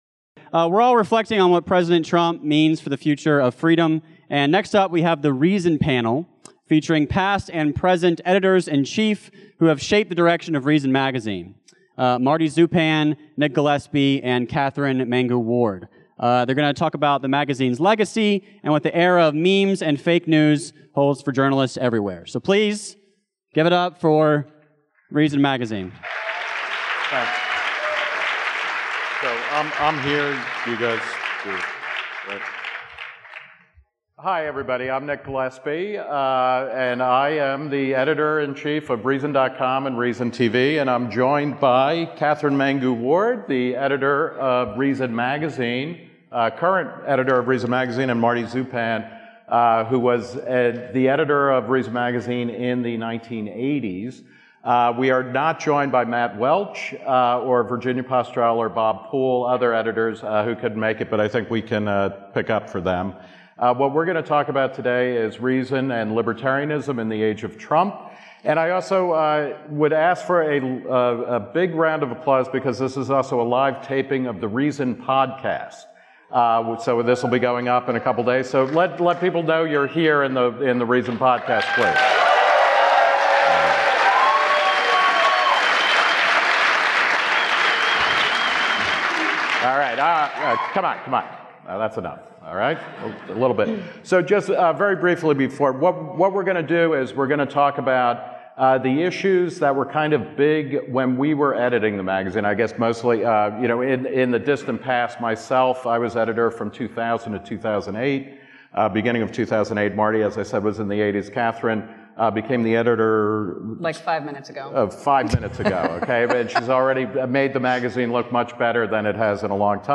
This podcast was recorded live on Friday, February 17.